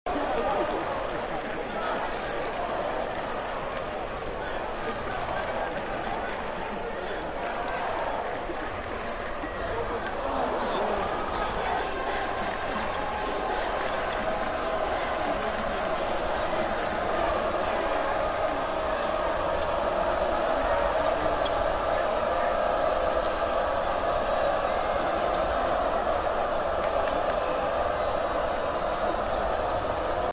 us_crowd.mp3